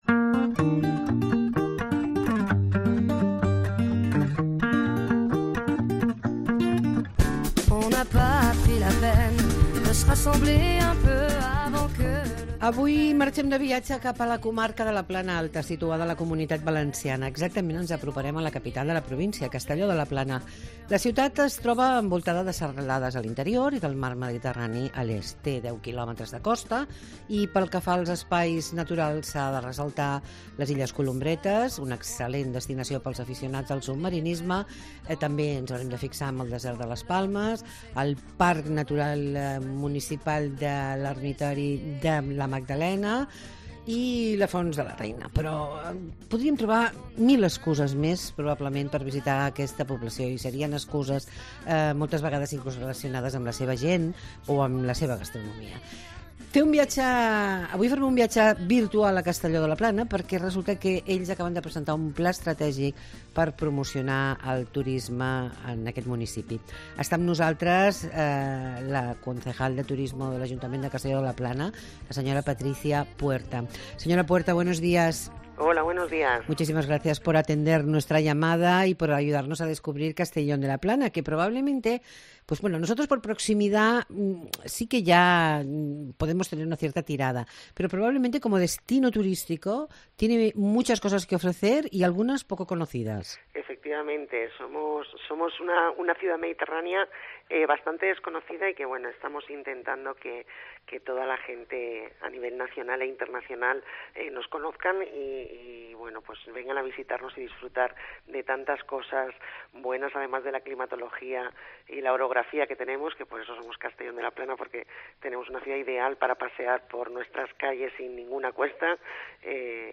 AUDIO: Hablamos de Castellón de la Plana con Patricia Puerta, concejala de Turismo
ENTREVISTA CASTELLÒ DE LA PLANA